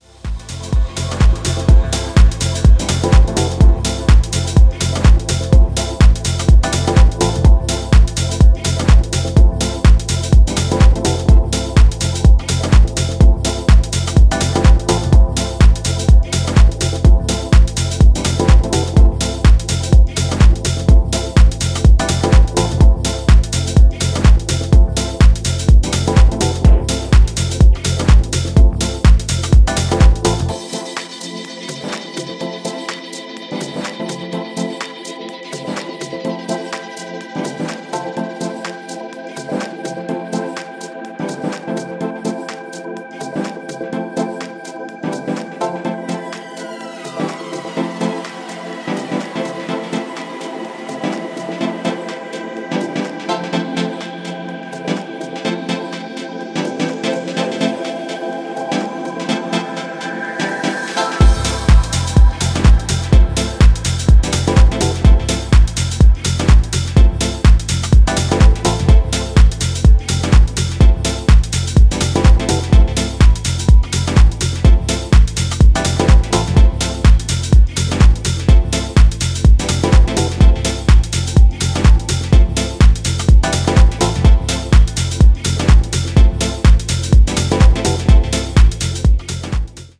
朧げにユーフォリックなムードを醸す
全曲端正に作り込まれたミニマル・テック・ハウスに仕上がっています！